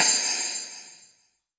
Crashes & Cymbals
MUB1 Crash 006.wav